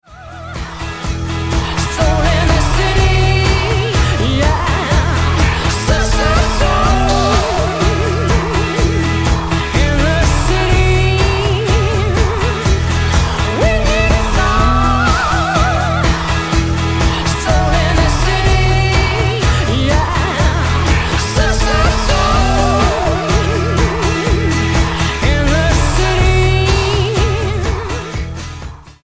British dance group
Style: Dance/Electronic